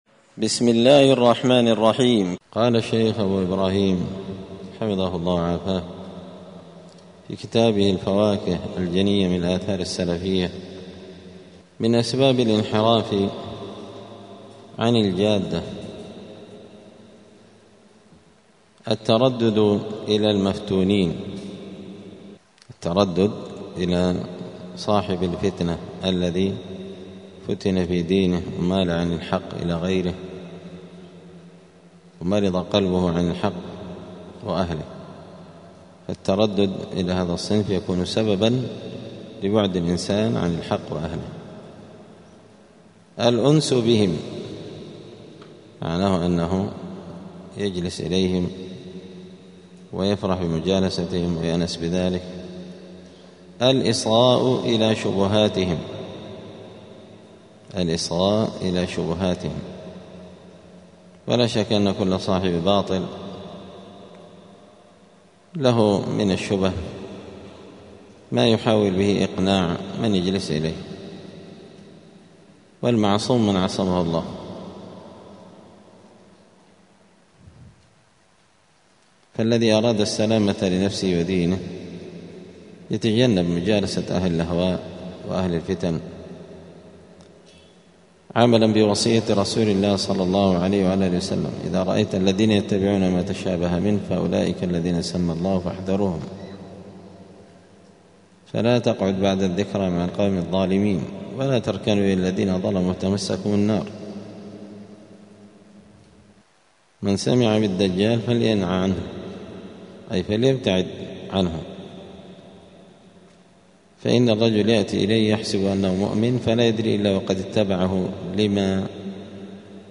دار الحديث السلفية بمسجد الفرقان بقشن المهرة اليمن
*الدرس التاسع والستون (69) {من أسباب الانحراف عن الجادة}*